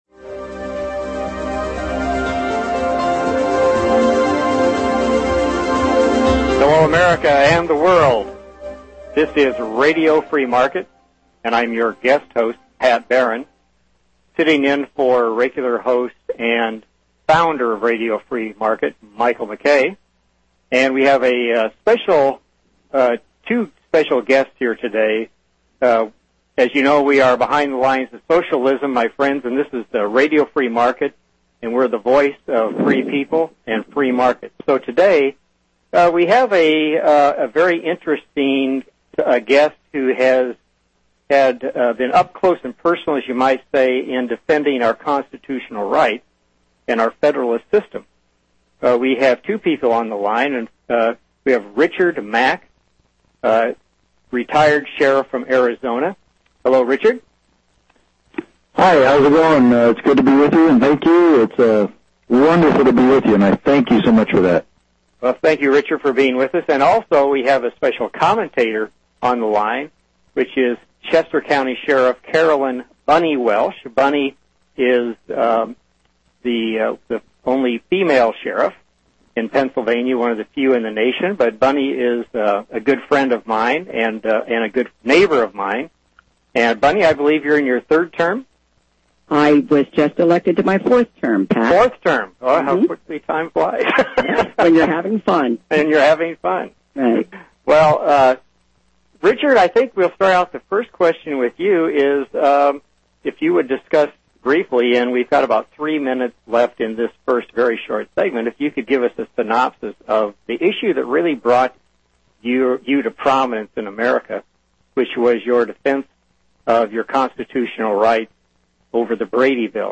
** LIVE CALL IN SHOW: Is the County Sheriff America's Last Hope? with Sheriff Richard Mack.** Sheriff Mack, now retired, is a staunch defender of the Natural Rights of citizens as recognized in the US Constitution.